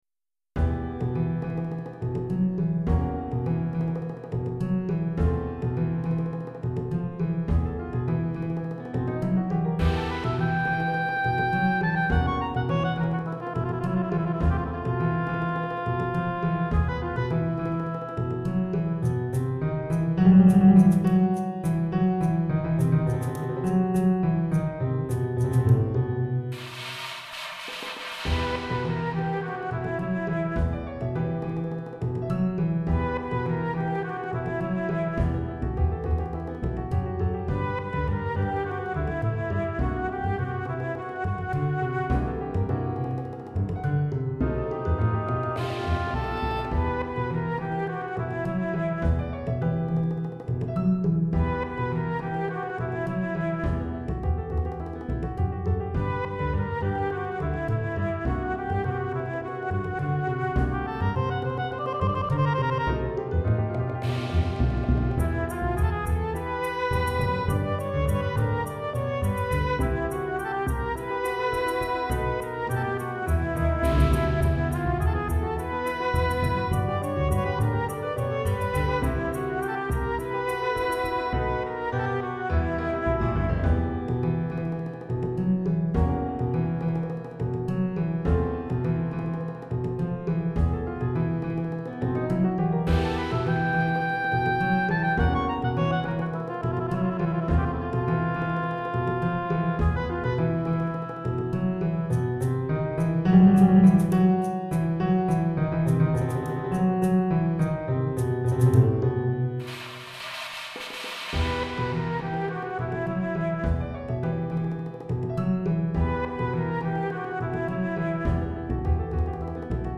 Chorale d'Enfants (8 à 11 ans) et Piano